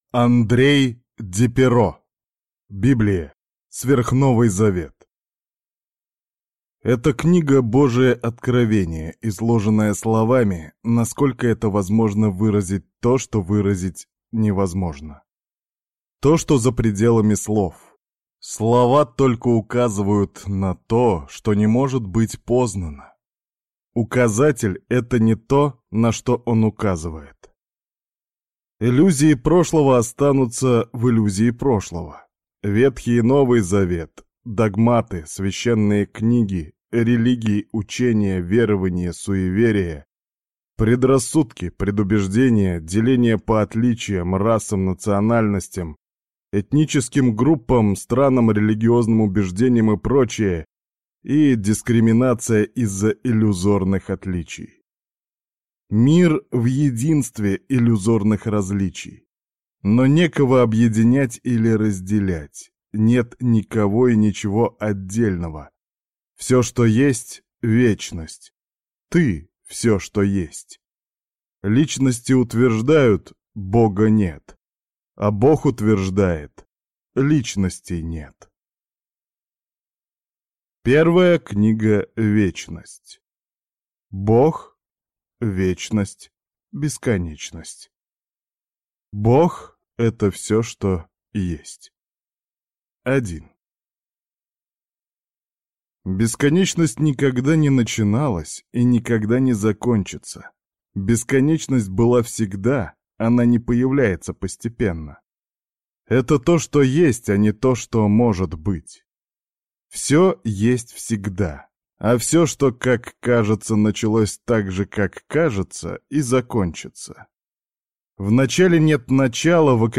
Аудиокнига Библия. Сверхновый Завет | Библиотека аудиокниг